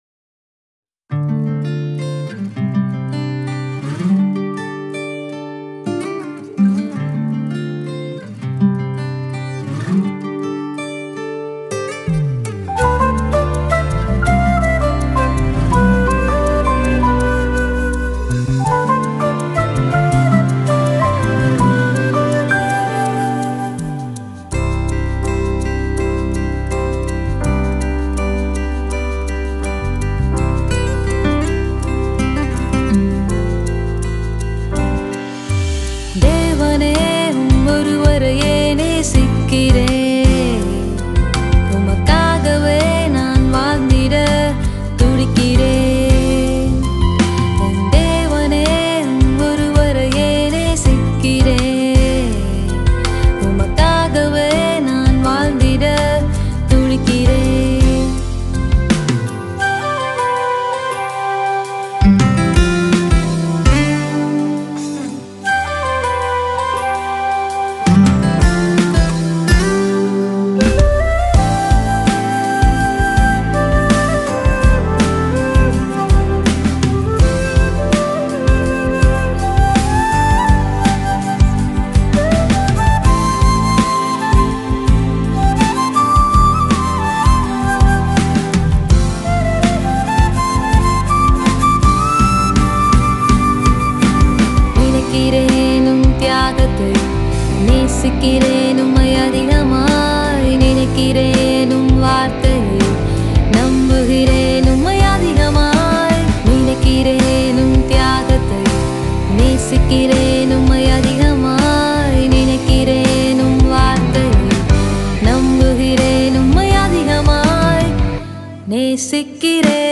Royalty-Free Gospel songs